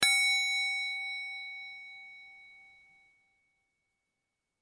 Звуки музыкального треугольника
10. Ударный музыкальный инструмент